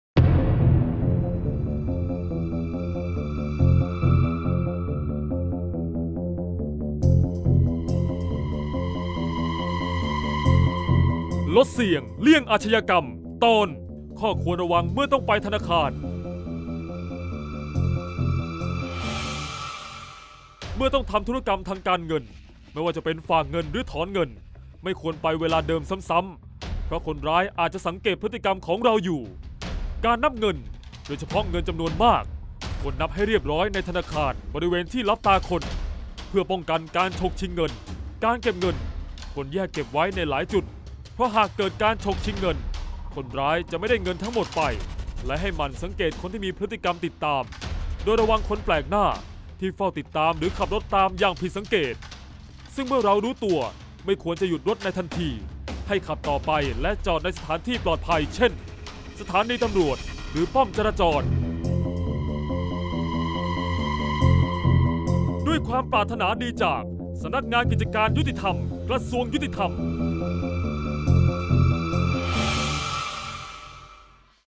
เสียงบรรยาย ลดเสี่ยงเลี่ยงอาชญากรรม 46-ระวังภัยเมื่อไปธนาคาร